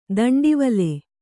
♪ daṇḍivale